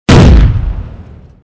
boum
Sonido FX 10 de 42
boum.mp3